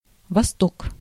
The Vostok programme (/ˈvɒstɒk, vɒˈstɒk/ VOST-ok, vost-OK; Russian: Восток, IPA: [vɐˈstok]
Ru-восток.ogg.mp3